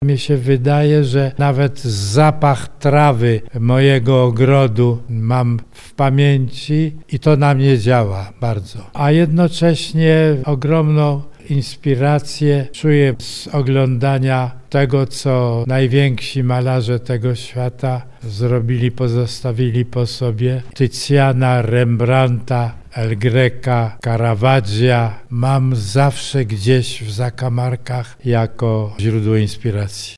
Ilustrator, malarz, rzeźbiarz, twórca plakatów i scenografii teatralnych – Józef Wilkoń gościł w Muzeum Literackim im. Józefa Czechowicza w Lublinie. Podczas spotkania opowiadał o swoich źródłach twórczości, książkach, które go inspirują, i tajemnicach sztuki ilustratorskiej.
Spotkanie z Józefem Wilkoniem w Muzeum Józefa Czechowicza